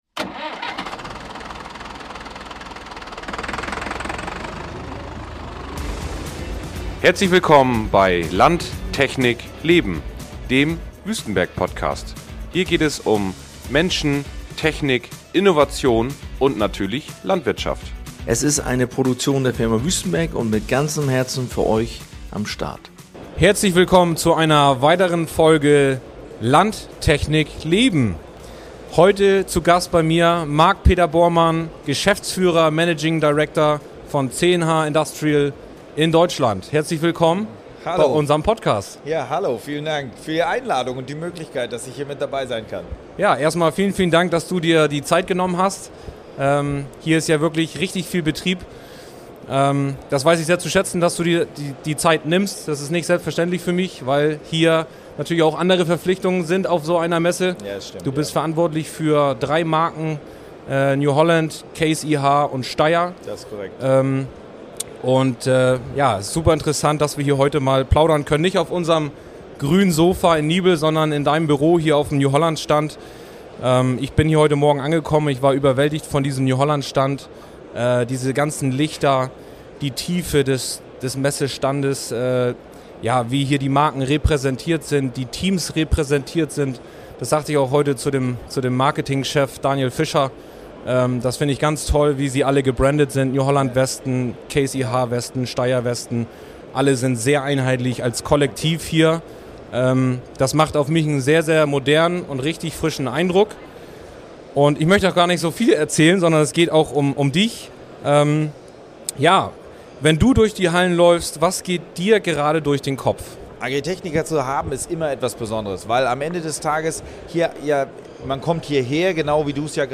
Live von der Agritechnica 2025 in Hannover!